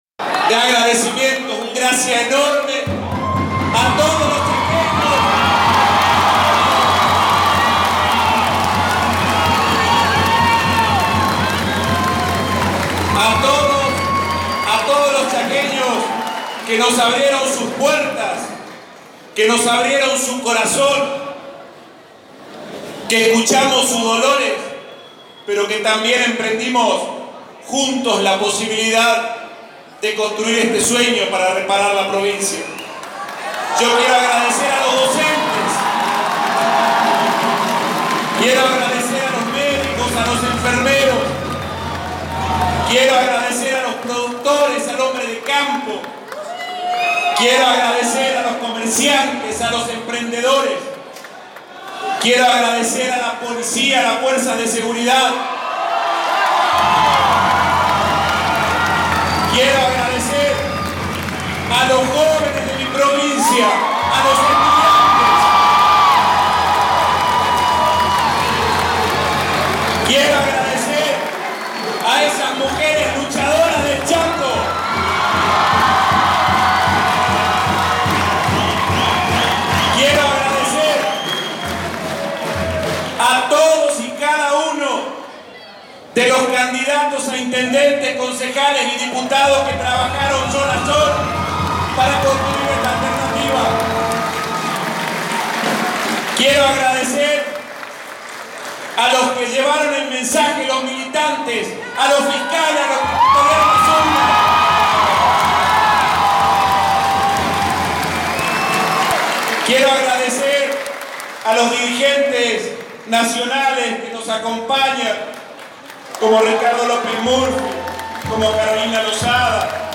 Audio. El discurso de Leandro Zdero: agradeció y llamó a una "transición ordenada"
Minutos después del discurso de Capitanich, Zdero subió al escenario de su sede partidaria y agradeció a los chaqueños y a su equipo de campaña.